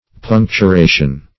Search Result for " puncturation" : The Collaborative International Dictionary of English v.0.48: Puncturation \Punc`tu*ra"tion\, n. The act or process of puncturing.